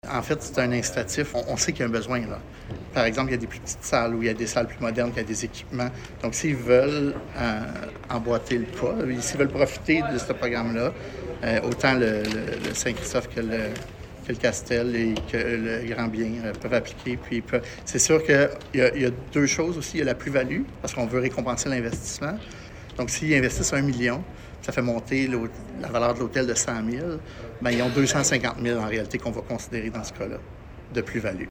Félix Dionne, Conseiller municipal et Responsable du développement commercial et touristique.